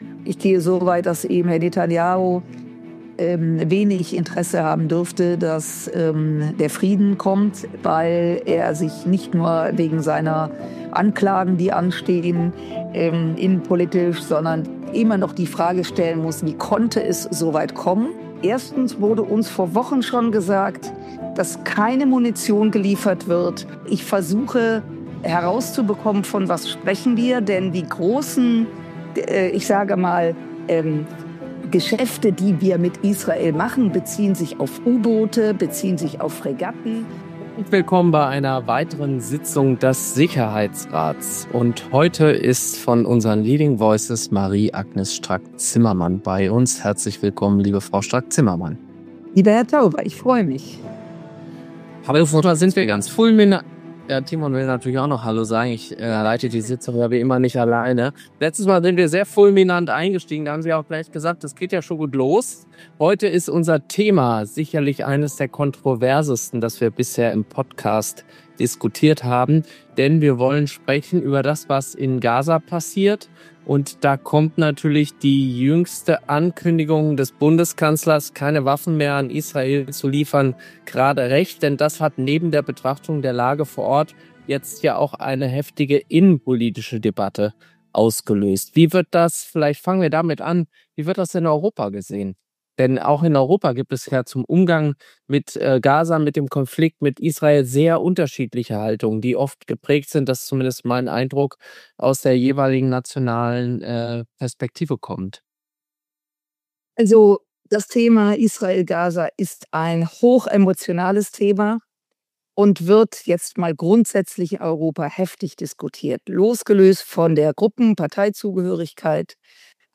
Peter Tauber spricht mit Marie-Agnes Strack-Zimmermann über eine Entscheidung, die international Wellen schlägt.